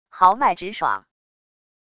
豪迈直爽wav音频
豪迈直爽wav音频生成系统WAV Audio Player